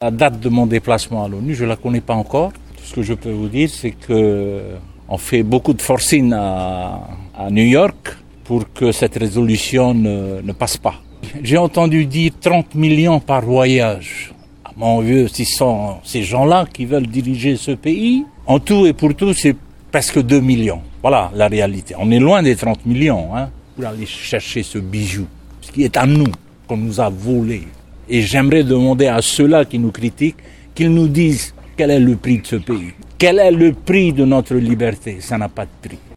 Oscar Temaru a décidé répondre à ses détracteurs :